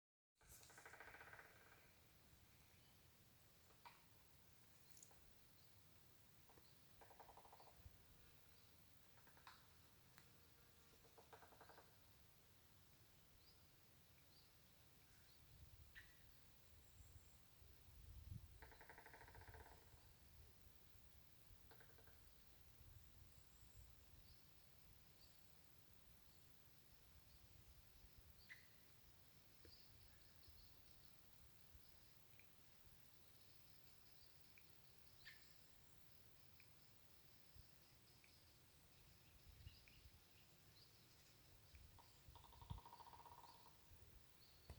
Three-toed Woodpecker, Picoides tridactylus
StatusSpecies observed in breeding season in possible nesting habitat